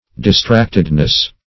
Search Result for " distractedness" : The Collaborative International Dictionary of English v.0.48: Distractedness \Dis*tract"ed*ness\, n. A state of being distracted; distraction.